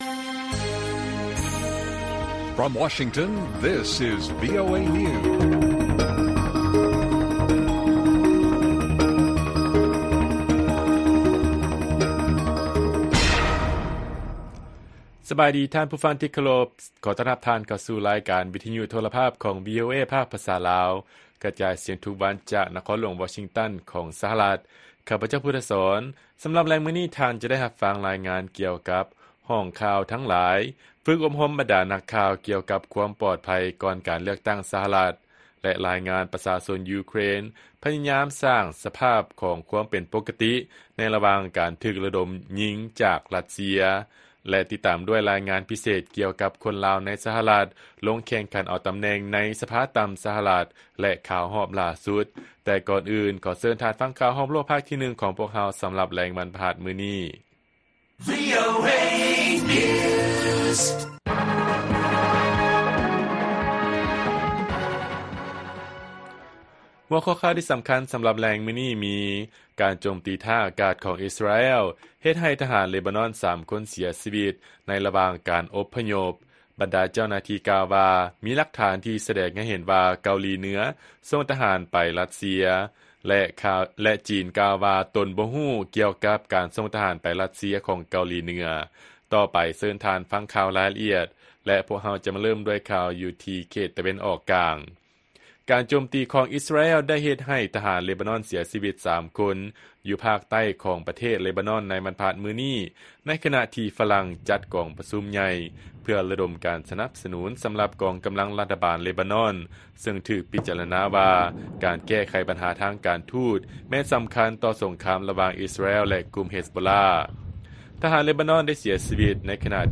ລາຍການກະຈາຍສຽງຂອງວີໂອເອລາວ: ການໂຈມຕີທາງອາກາດຂອງ ອິສຣາແອລ ເຮັດໃຫ້ທະຫານ ເລບານອນ ສາມຄົນເສຍຊີວິດ ໃນລະຫວ່າງການອົບພະຍົບ